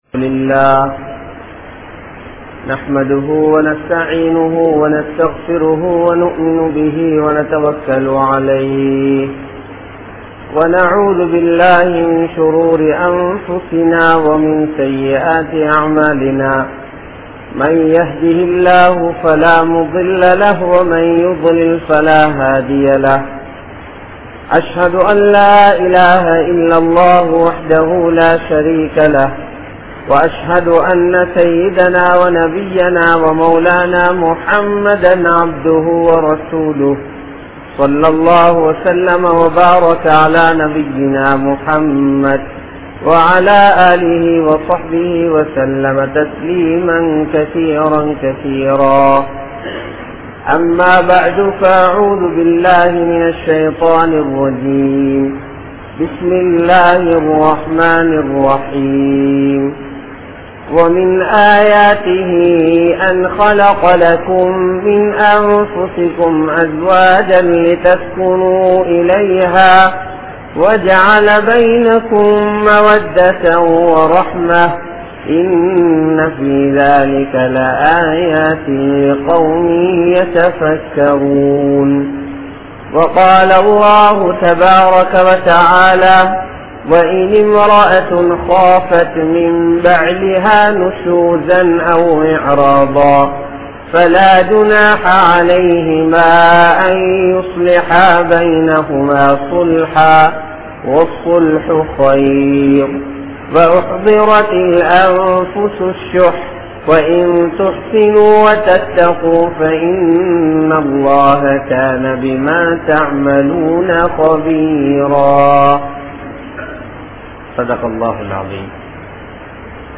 Thirumana Vaalkai | Audio Bayans | All Ceylon Muslim Youth Community | Addalaichenai